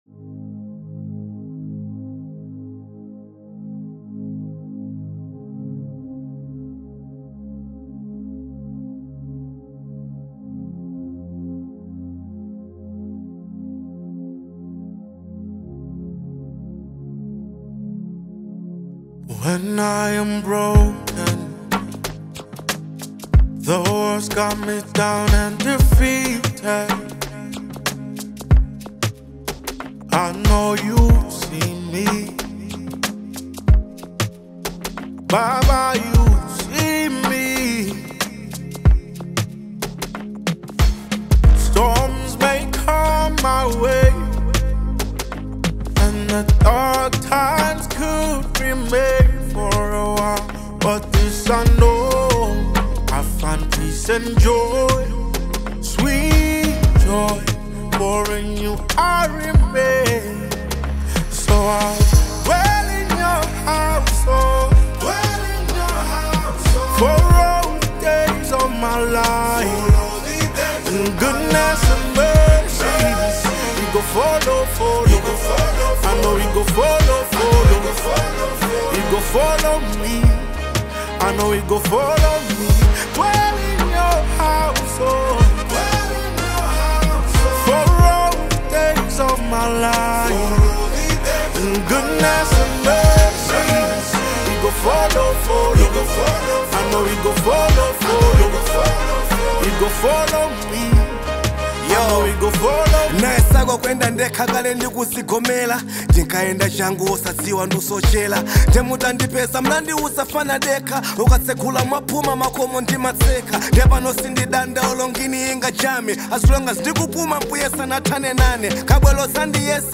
A Spiritually Uplifting Anthem
smooth, melodic voice